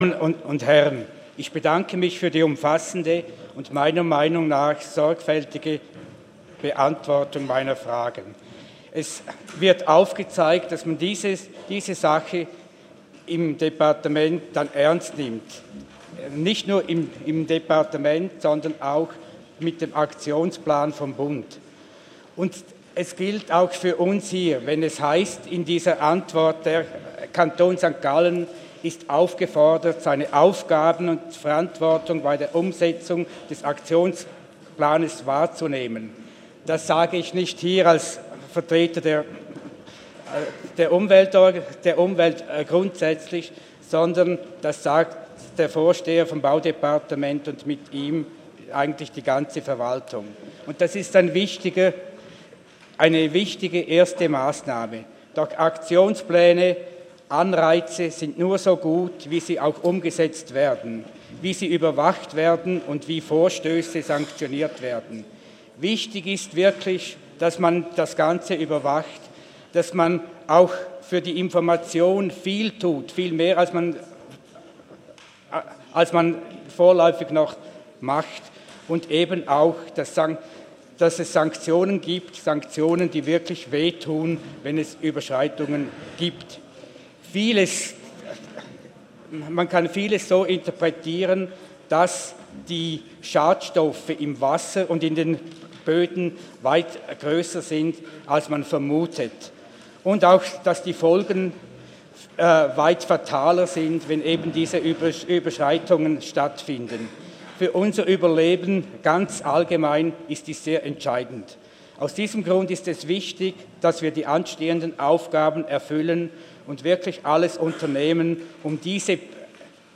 23.4.2018Wortmeldung
Session des Kantonsrates vom 23. und 24. April 2018